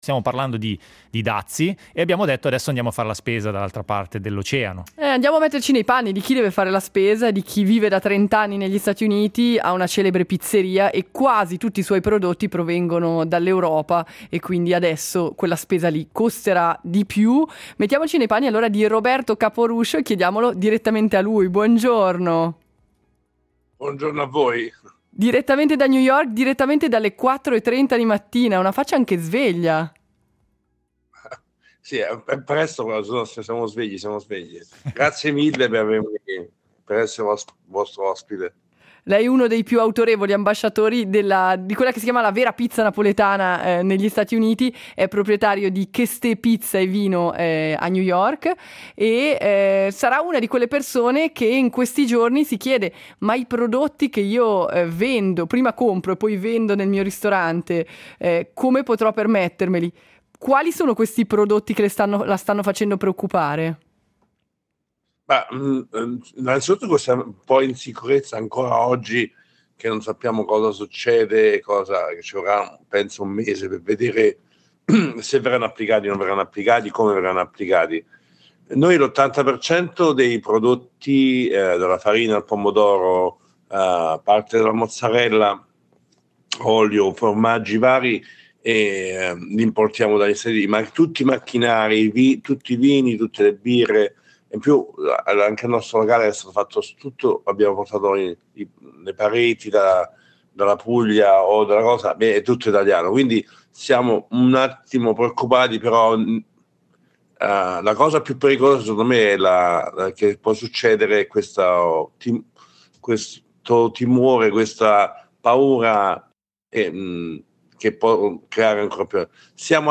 Oggi si trova a dover fare i conti con l’incremento dei prezzi a causa dei dazi. Si è svegliato alle 4.30 del mattino per raccontarci in prima persona come sta affrontando questa nuova sfida.